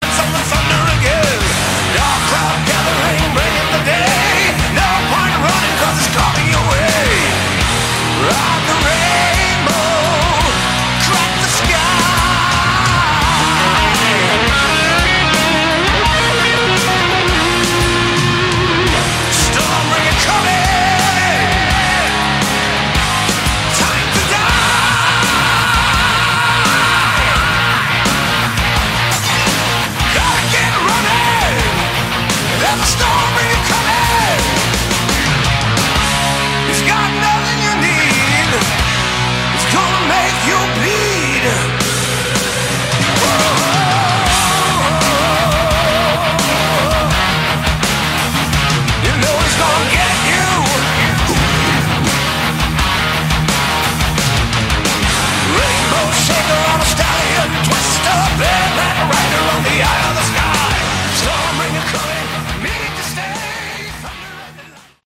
Category: Melodic Metal
vocals